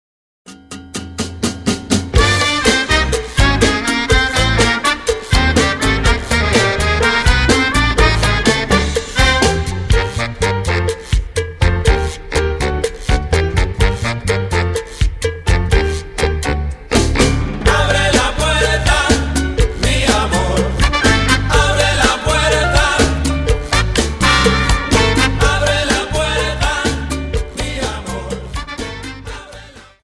Dance: Cha Cha